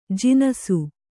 ♪ jinasu